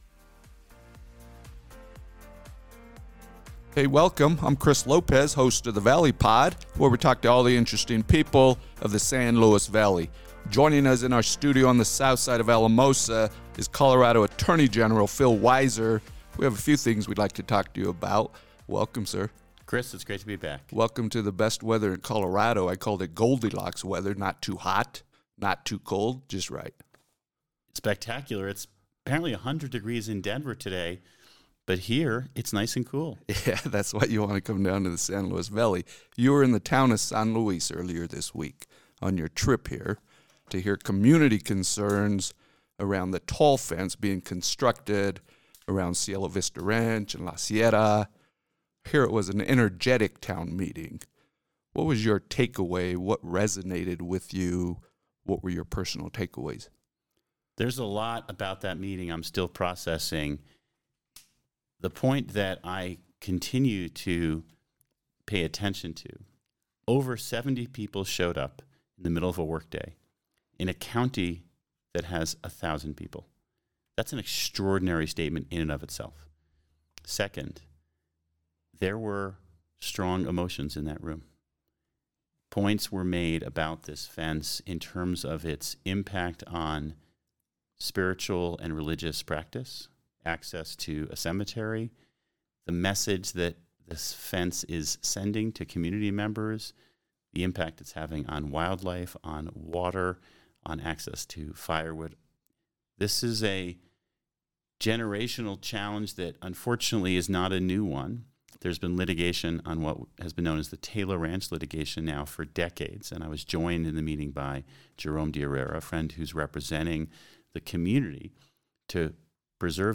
Colorado Attorney General Phil Weiser joins the Valley Pod as the most recent guest to discuss ongoing things in CO and the Valley